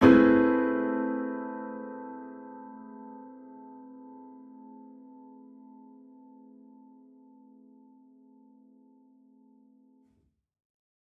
Index of /musicradar/gangster-sting-samples/Chord Hits/Piano
GS_PiChrd-Amin9maj7.wav